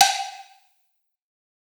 Perc [Loaded].wav